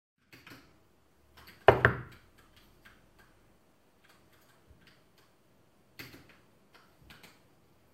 knock.ogg